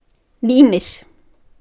mimir.mp3